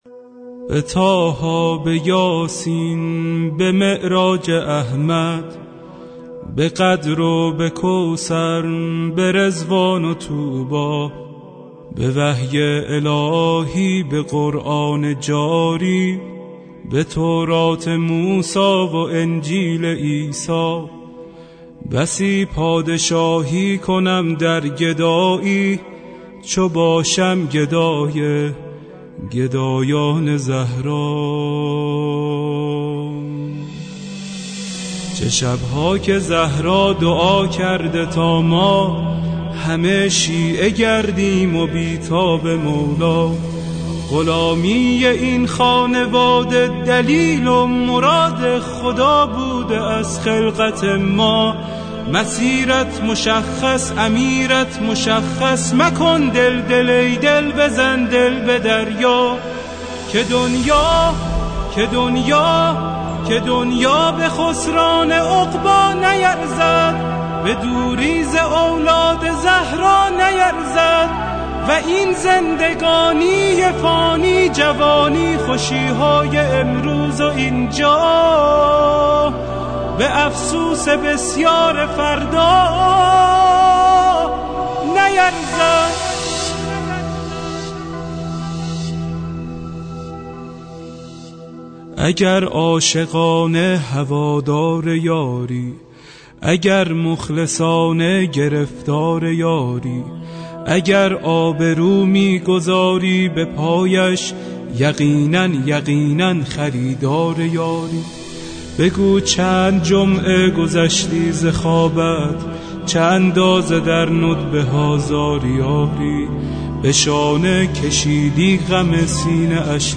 • دكلمه اي زيبا با موضوع حق گستر واقعي